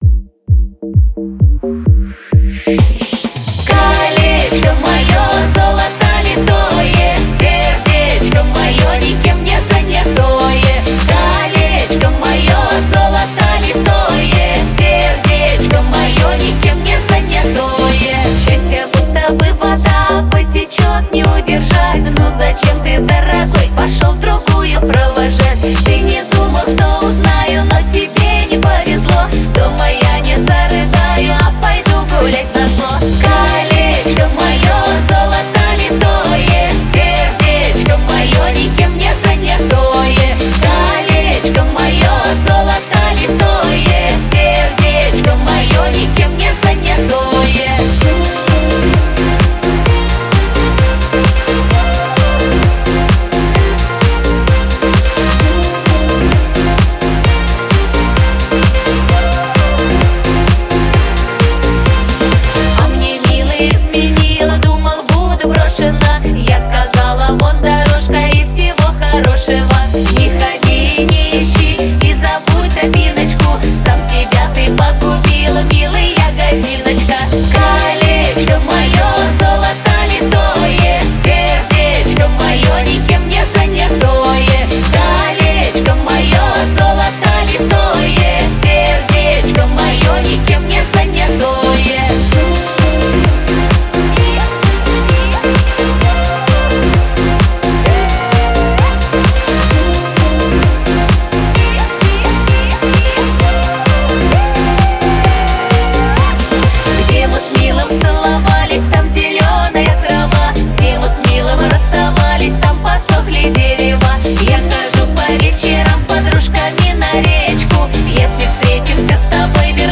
Русские народные песни